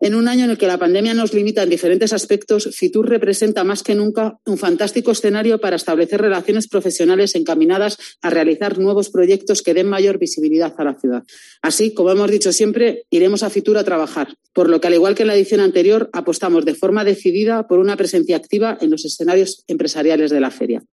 Esmeralda Campos, concejala de Turismo de Logroño